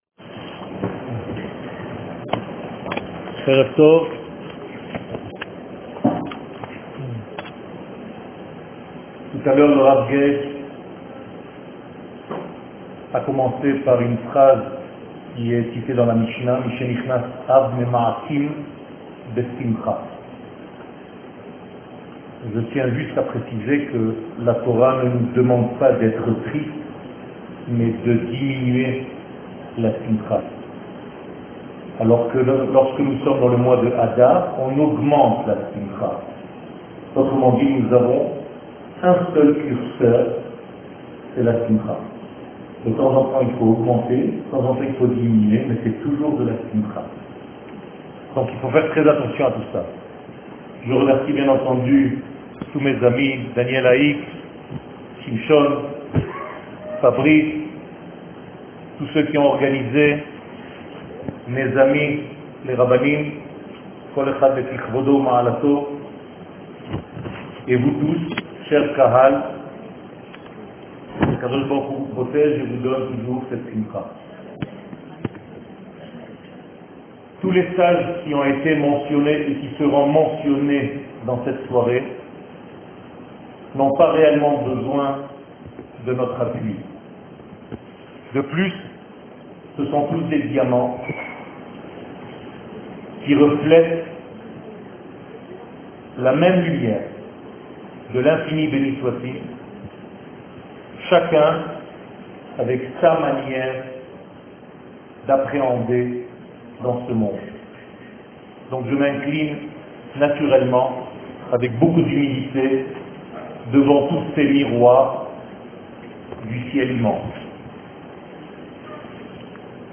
Thora Box Eretz Israel שיעור מ 15 יולי 2018 12MIN הורדה בקובץ אודיו MP3 (5.59 Mo) הורדה בקובץ אודיו M4A (1.43 Mo) TAGS : Rosh Hodesh et mois de l'annee Omer Tisha b'Av Torah et identite d'Israel שיעורים קצרים